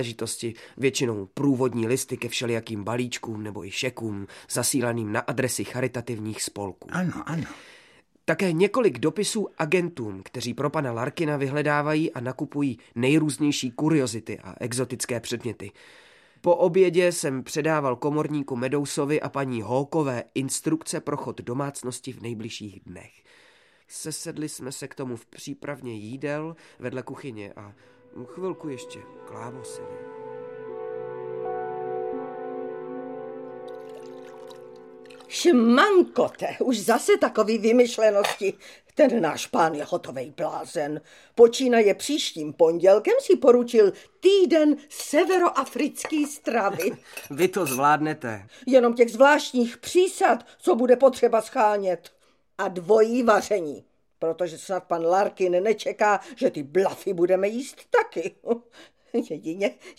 Audiobook
Read: Kryštof Hádek